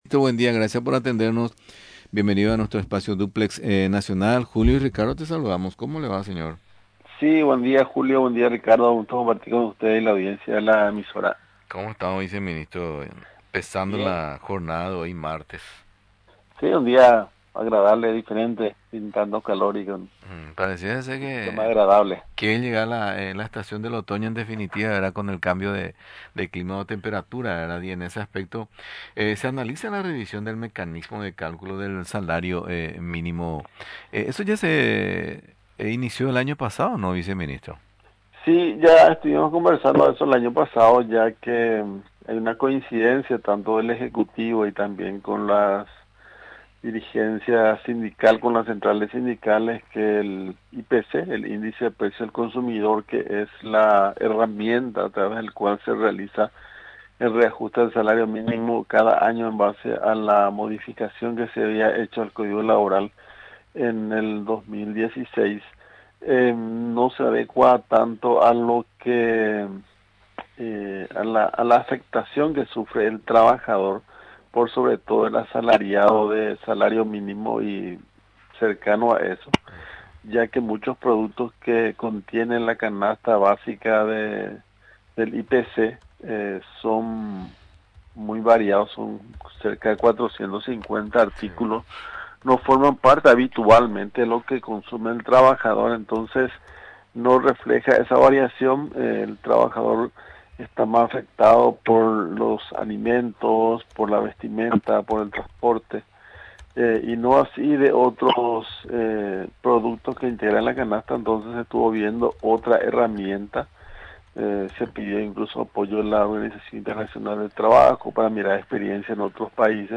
Se analiza la revisión del mecanismo de cálculo del salario mínimo, explicó este martes el viceministro de Trabajo, César Segovia.
Durante la entrevista en Radio Nacional del Paraguay, refirió que existen propuestas en relación a este tema.